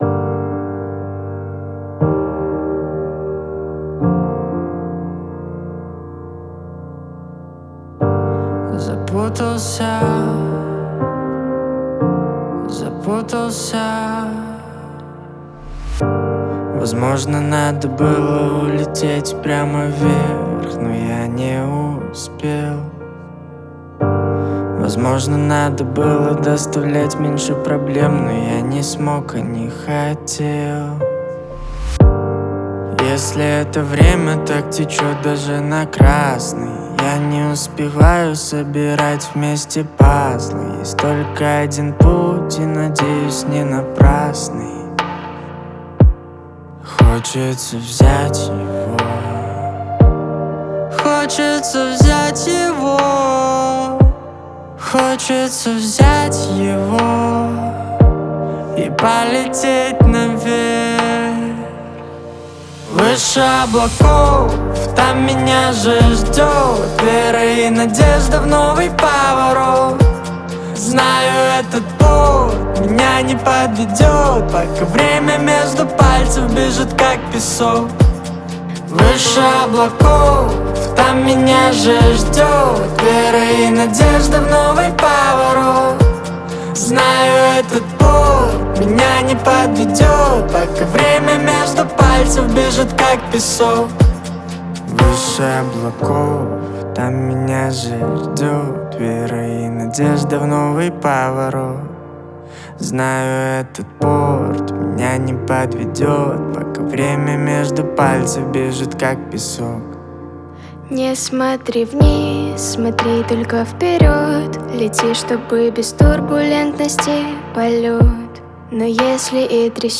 это вдохновляющая песня в жанре поп